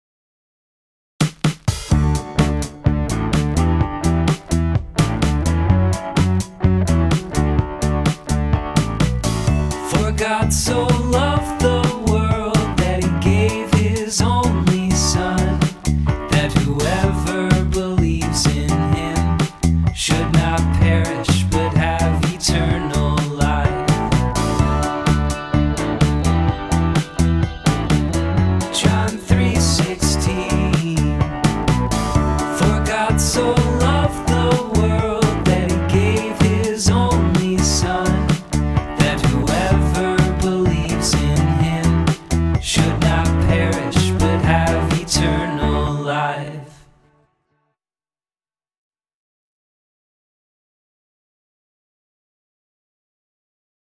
Bible Verse Song - John 3:16
mem-verse-song-apr-2025.mp3